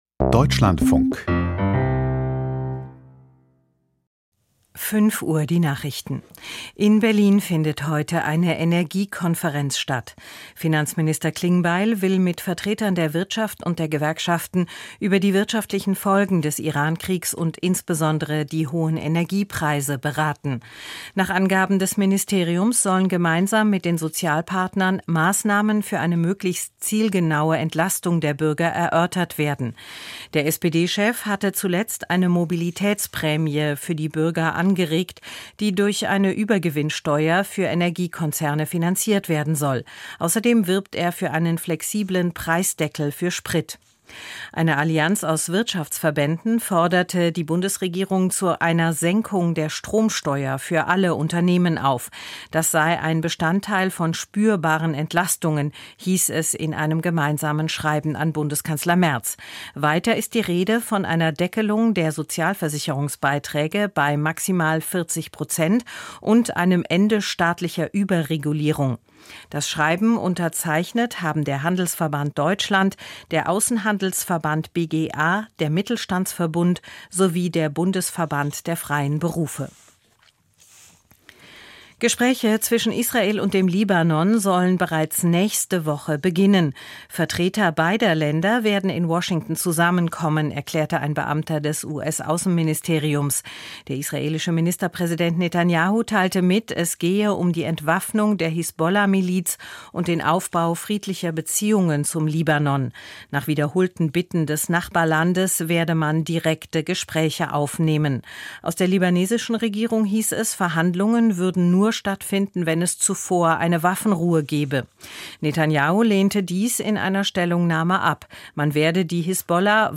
Die Nachrichten vom 10.04.2026, 05:00 Uhr